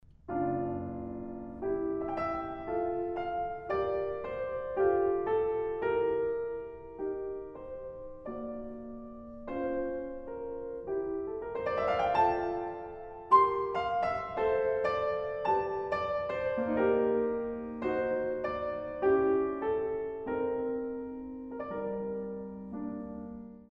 in D Minor